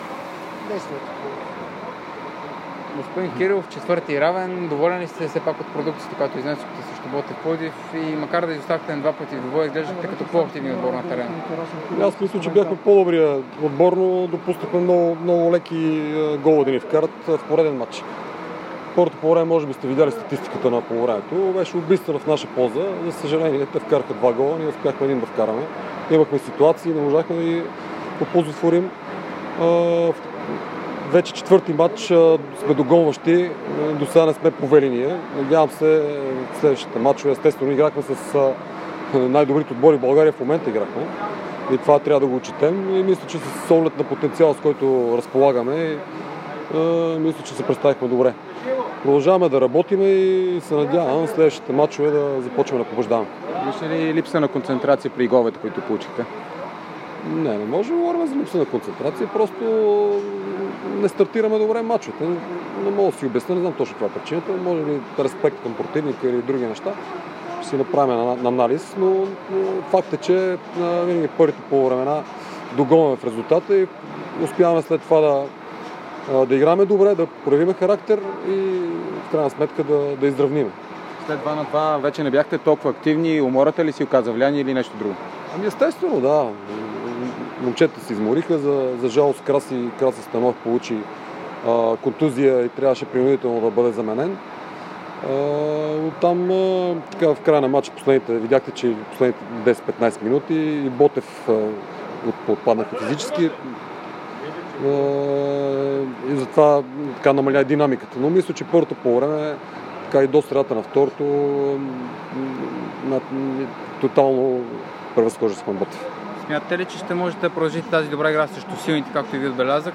Росен Кирилов след мача на Етър с Ботев Пловдив, който завърши при равен резултат 2:2.
Чуйте какво още сподели треньорът на "болярите".